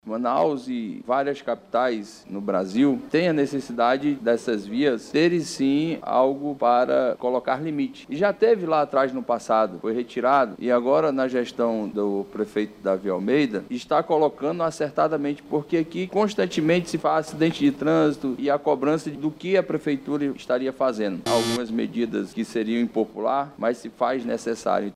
O vereador Elan Alencar, do Democracia Cristã, defendeu a volta dos radares eletrônicos nas vias da cidade, que serão implantados pela Prefeitura de Manaus.